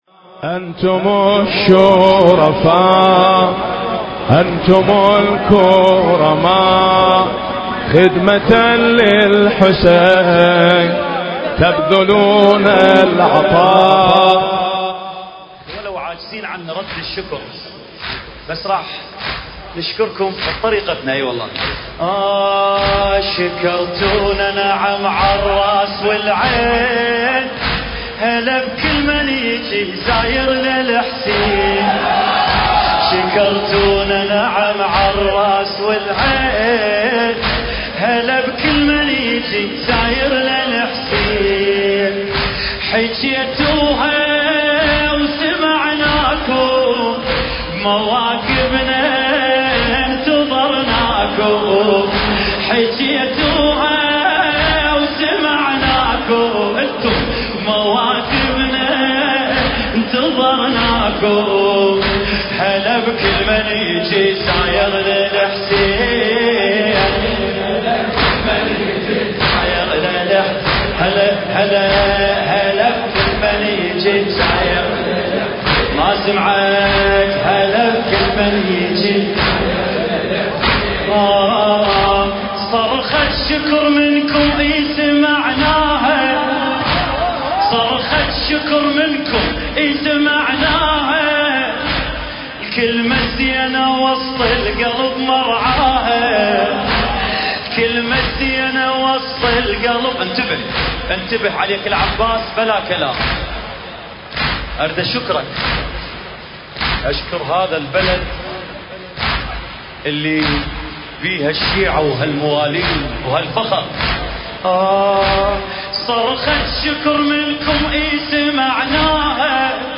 المراثي
المكان: حسينية آية الله العظمى السيد عدنان الغريفي (رحمه الله) إيران – المحمرة